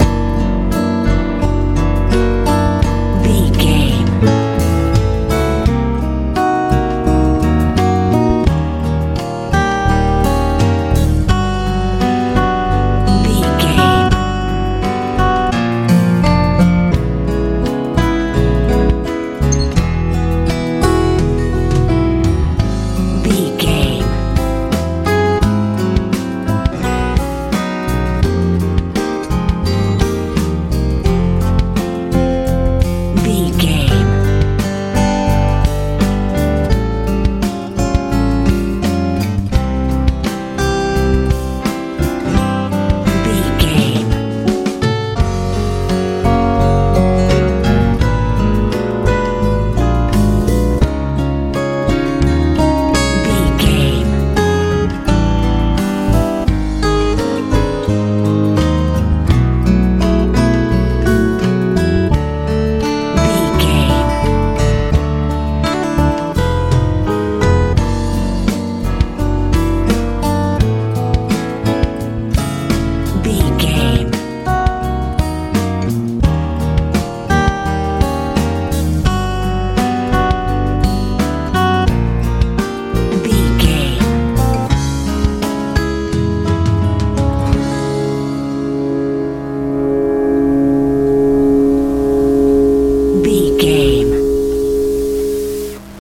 lite pop
Aeolian/Minor
soothing
piano
acoustic guitar
bass guitar
drums
80s
sweet
relaxed